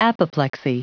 Prononciation du mot apoplexy en anglais (fichier audio)
Prononciation du mot : apoplexy